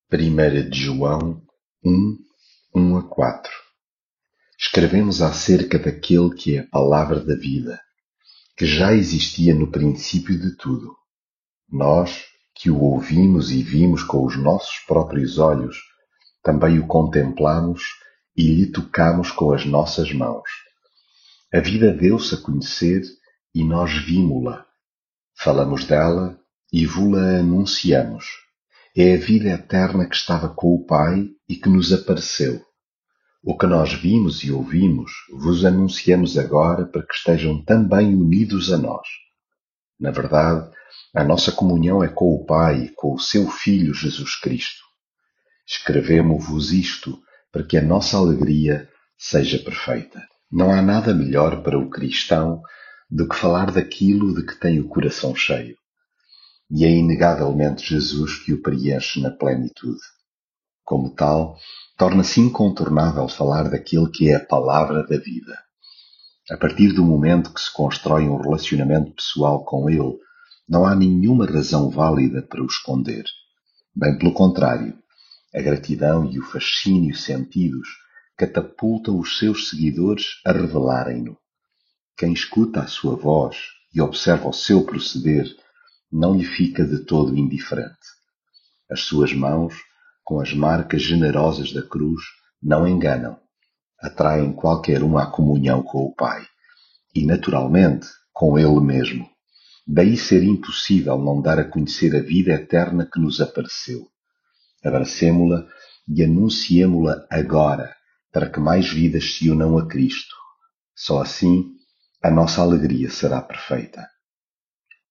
devocional 1 joão leitura bíblica Escrevemos acerca daquele que é a Palavra da vida, que já existia no princípio de tudo.